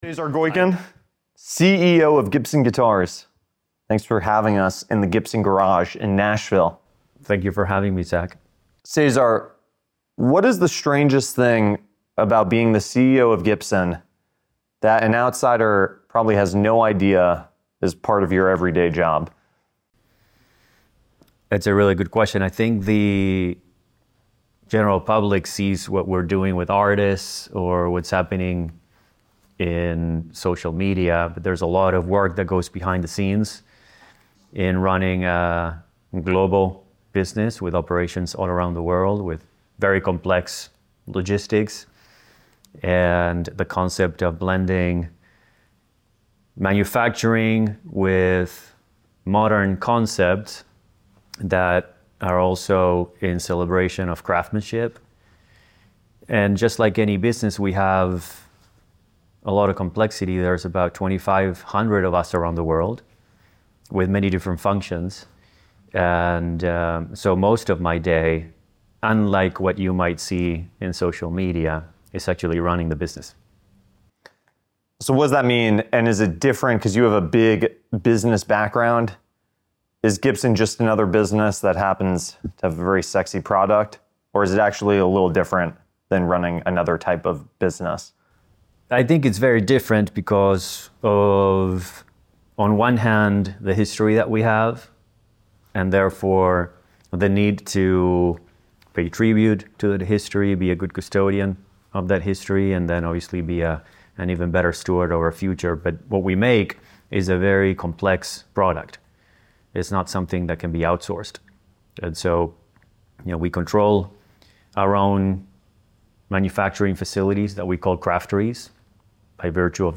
These are not your typicle PR-polished interviews they're candid discussions about the challenges, triumphs, and pivotal moments that shape careers.